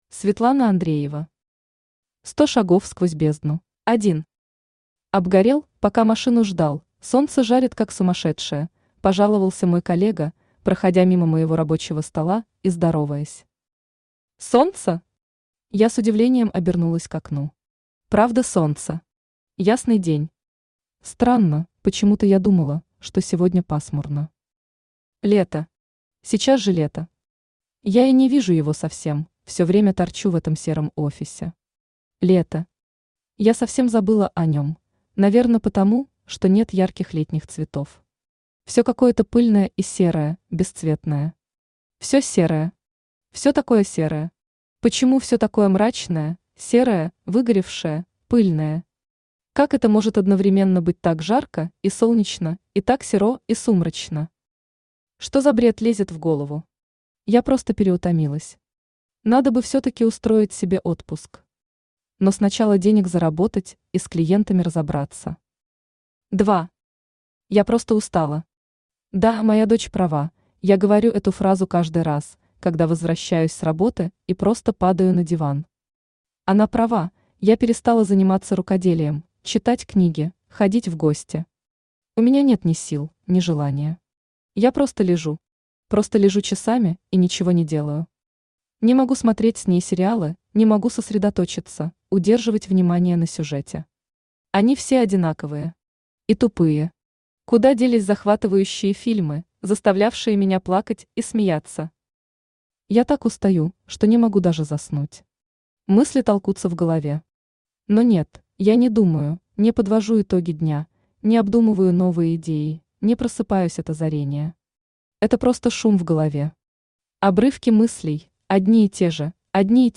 Аудиокнига Сто шагов сквозь бездну | Библиотека аудиокниг
Aудиокнига Сто шагов сквозь бездну Автор Светлана Евгеньевна Андреева Читает аудиокнигу Авточтец ЛитРес.